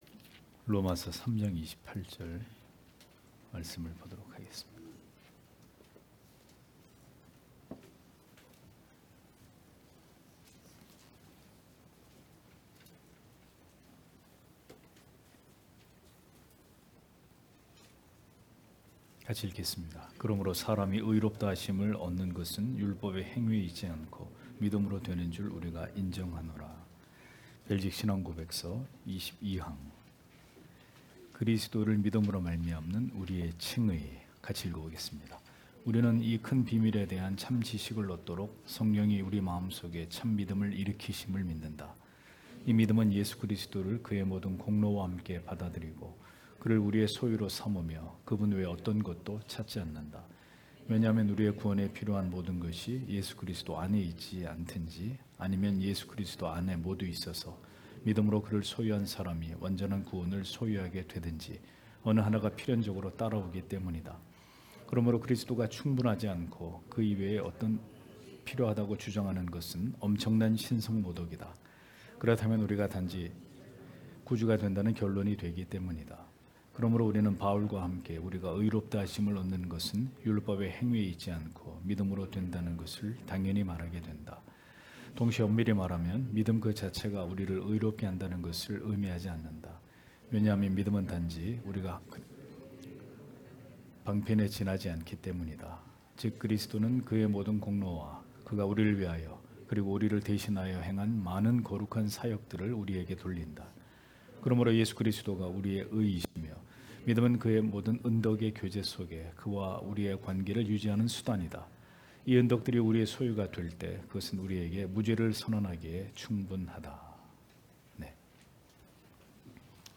주일오후예배 - [벨직 신앙고백서 해설 23] 제22항 그리스도를 믿음으로 말미암는 우리의 칭의(롬 3장 28절)